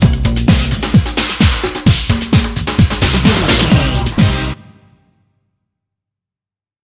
Aeolian/Minor
Fast
drum machine
synthesiser
electric piano
bass guitar
conga